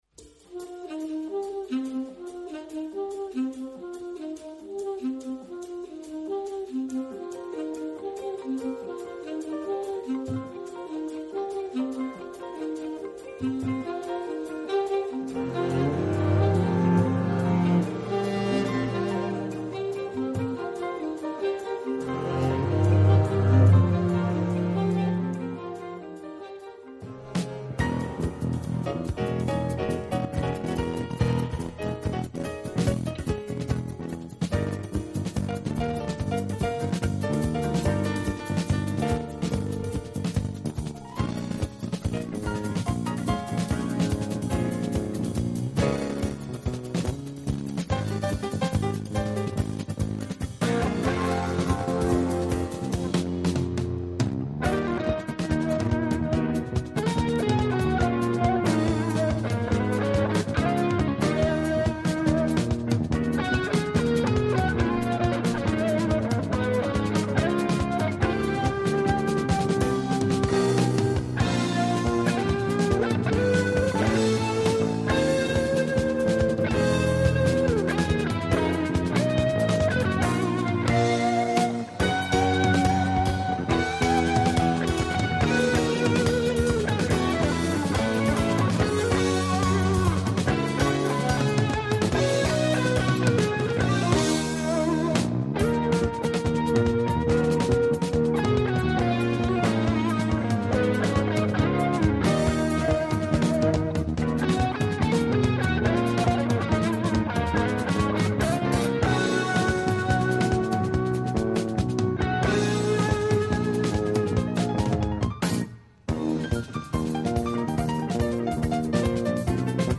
Centraal staat de promotie van jazz en beyond. Deze keer zomers vinyl.